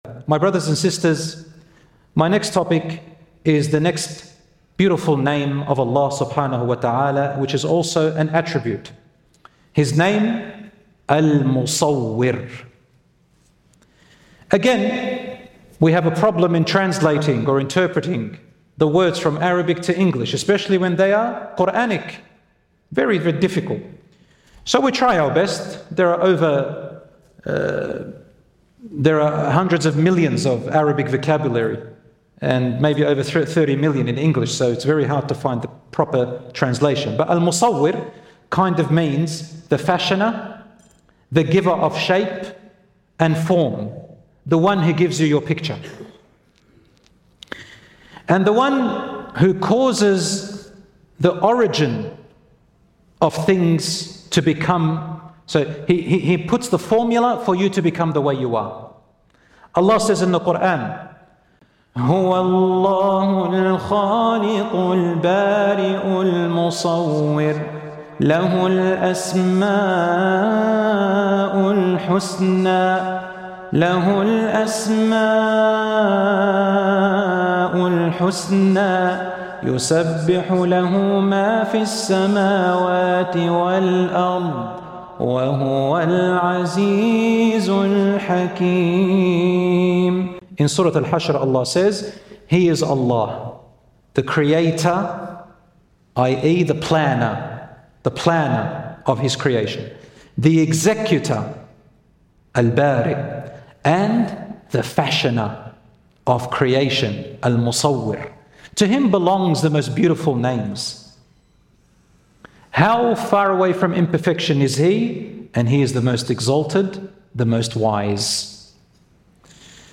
In this lecture, we explore the beautiful name of Allah "Al-Musawwir" (The Fashioner), reflecting on its meaning, Quranic context and its relevance to creation, human identity and self-worth in the modern world.